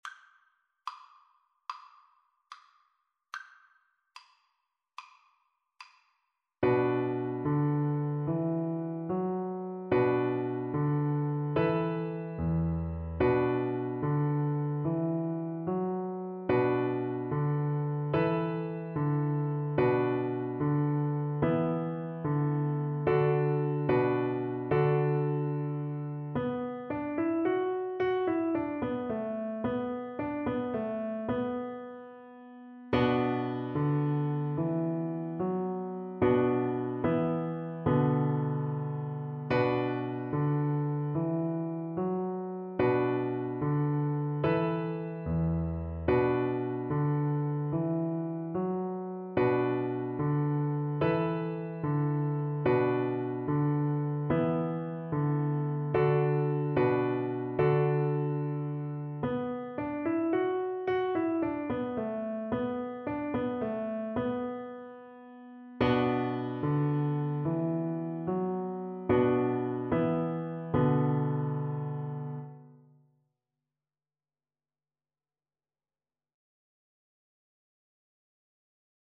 Voice
B minor (Sounding Pitch) (View more B minor Music for Voice )
12/8 (View more 12/8 Music)
Moderato .=100
A4-D6
Traditional (View more Traditional Voice Music)
world (View more world Voice Music)
adour_badour_VOICE_kar3.mp3